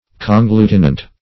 Search Result for " conglutinant" : The Collaborative International Dictionary of English v.0.48: Conglutinant \Con*glu"ti*nant\, a. [L., conglutinans, p. pr.]